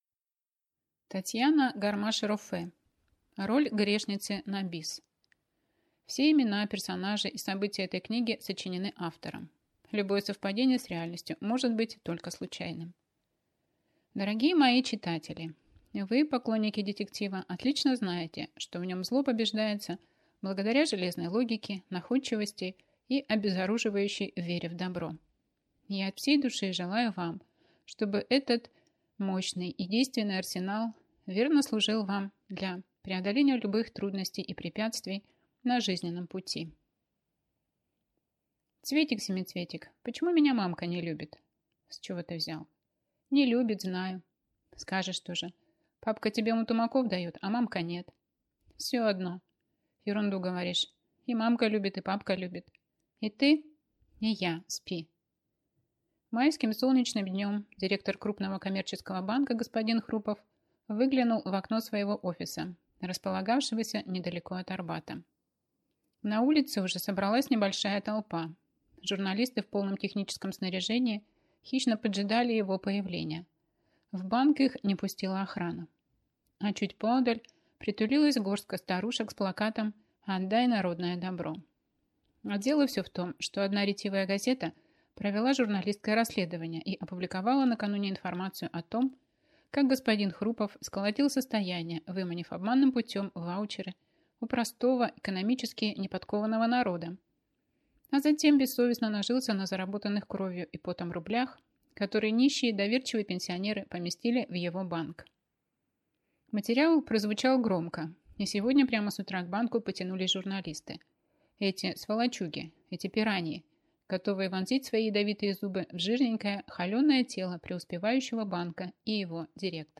Аудиокнига Роль грешницы на бис - купить, скачать и слушать онлайн | КнигоПоиск